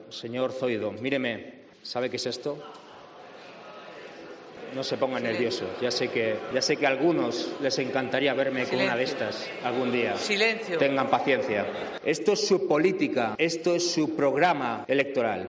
Estos son los fragmentos más polémicos de sus discursos: